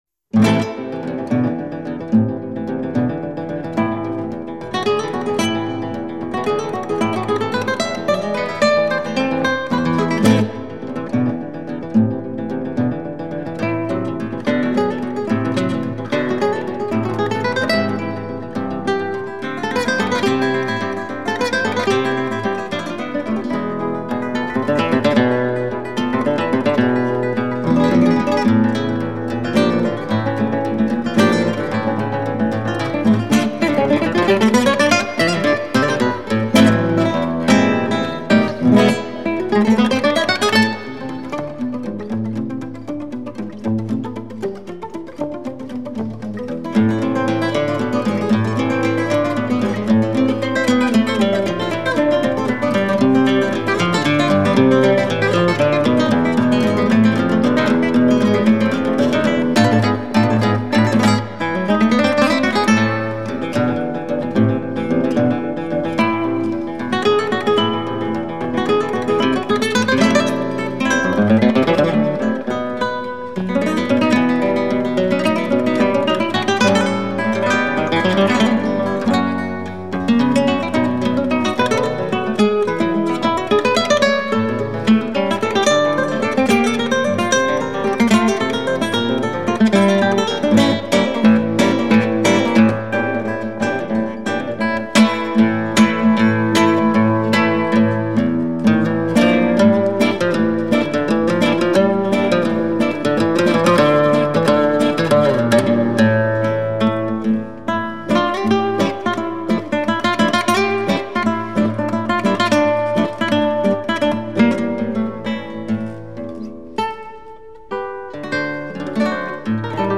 Жанр: Guitar
Плейлис: Spanish Guitar